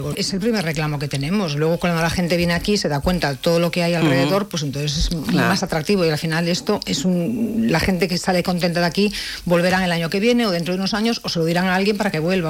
La regidora del Partit Popular de Calella, Celine Coronil, ha fet balanç de l’actualitat local en una entrevista al matinal de RCT on ha abordat temes clau com el turisme, el comerç local i neteja.